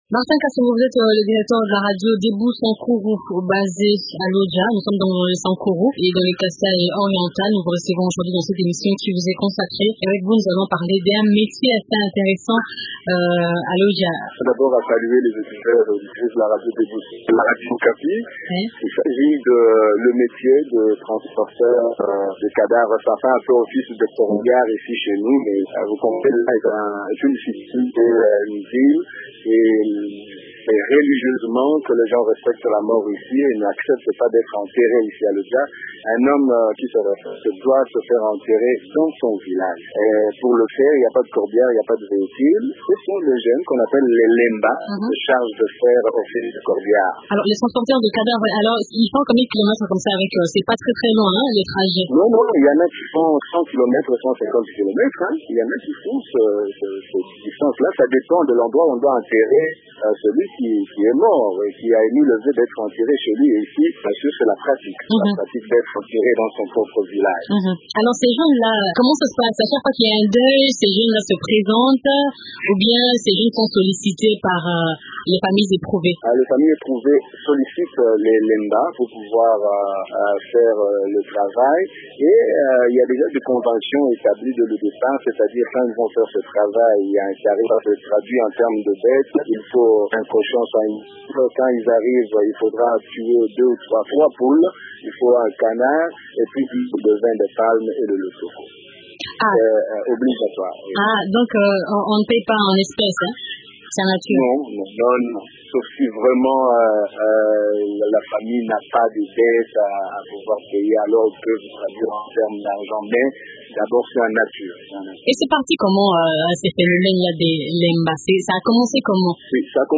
Studio Radio Debout Sankuru de Lodja